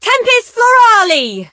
rosa_ulti_vo_03.ogg